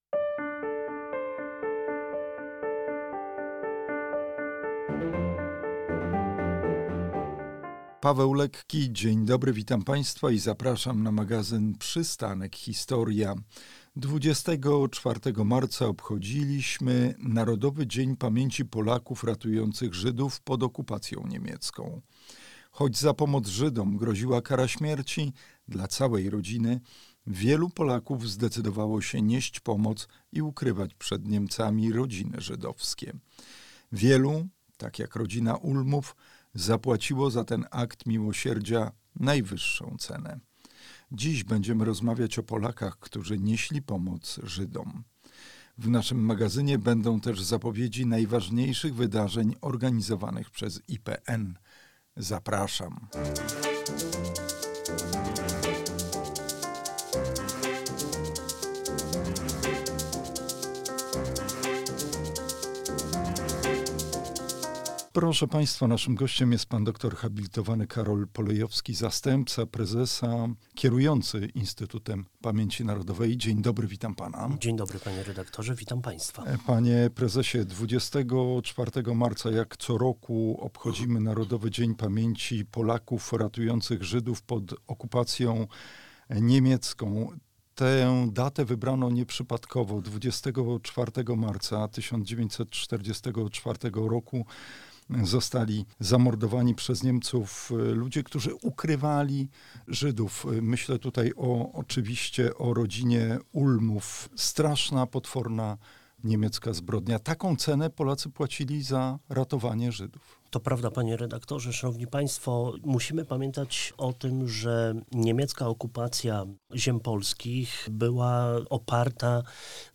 O Polakach ratujących Żydów pod okupacja niemiecką mówi gość naszego magazynu zastępca prezesa IPN dr hab. Karol Polejowski, kierujący obecnie pracami Instytutu Pamięci Narodowej.